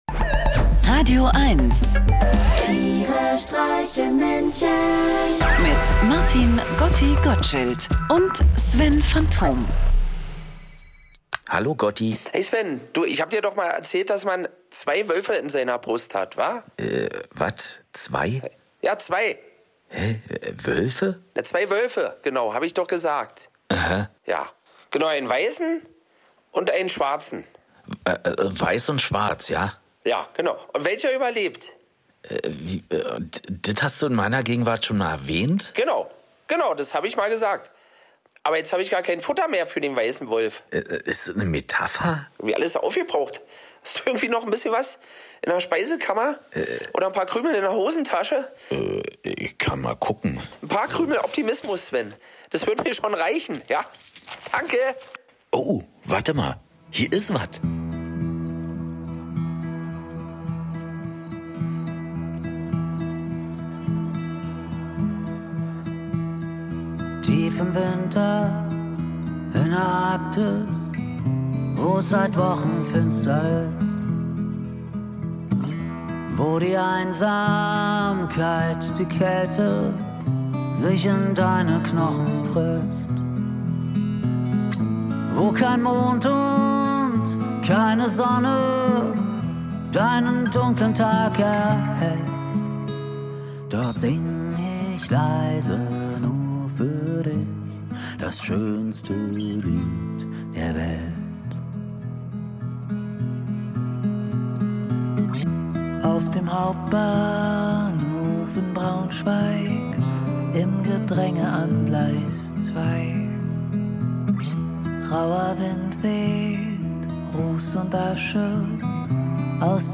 Einer liest, einer singt und dabei entstehen absurde, urkomische, aber auch melancholische Momente.
Comedy